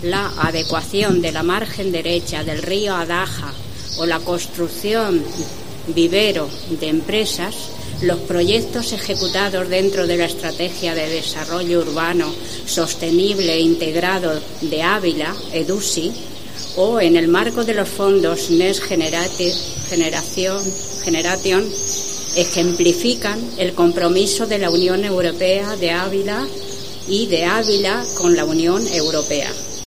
Lectura del manifiesto del día de Europa en Ávila